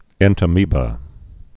(ĕntə-mēbə)